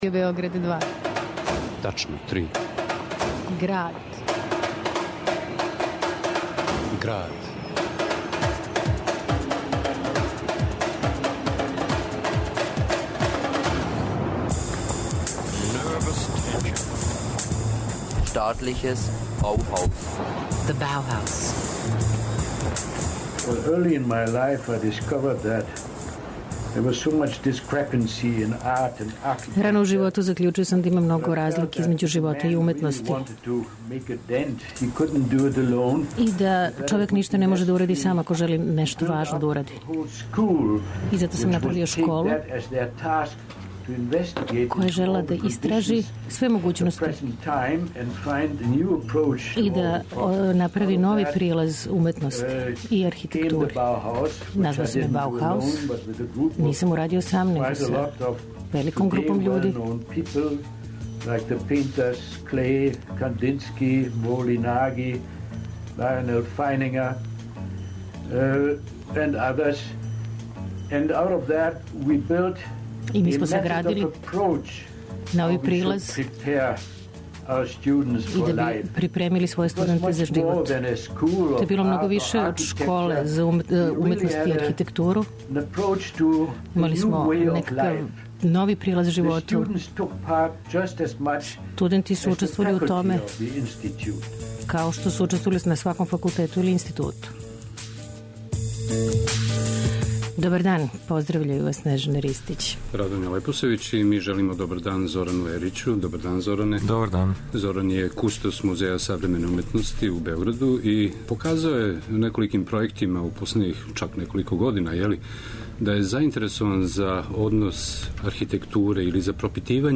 Чуће се и гласови Гропиуса и Миса ван дер Роеа, те инсерти из програма којима је свет 2009. обележио 90 година Баухауса.